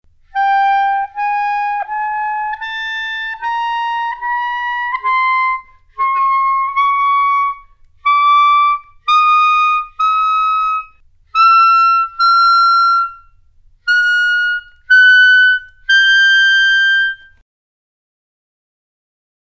One-Octave Microtonal Scales
Scales can be formed from microtones that present fascinating pitch or timbre relationships when written for E-flat clarinet.
An equidistant scale of sixteen 3/8 tones is represented in Example #11.
16 - E-flat Clarinet - Track 16 - CD2.mp3